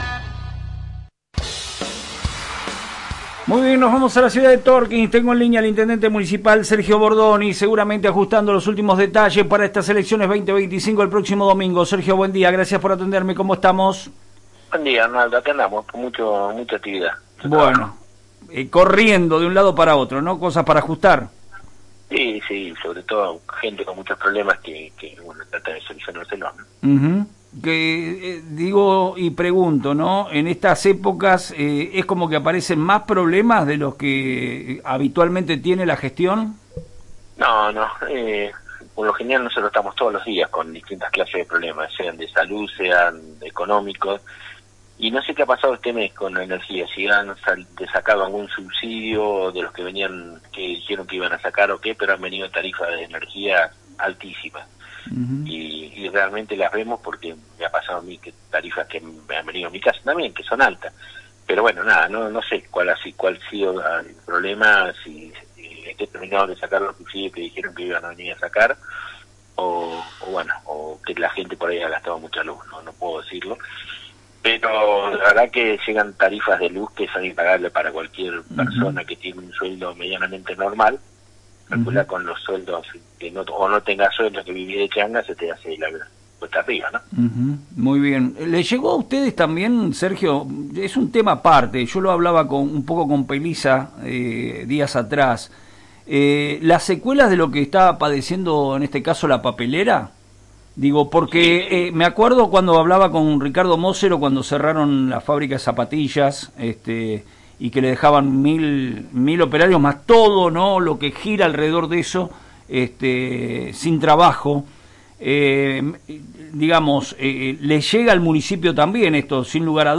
El Intendente Municipal, Sergio Bordoni participó de una entrevista en la mañana de FM Reflejos; dejó datos relevantes sobre la dramática situación que viven los trabajadores de la papelera y de todo el distrito frente al modelo económico que impone el gobierno nacional. Habló de incremento de la morosidad en la recaudación municipal y asegura seguir avanzando en el cuidado ambiental mediante un manejo adecuado de los residuos y su tratamiento.